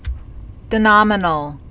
(dĭ-nŏmə-nəl, dē-)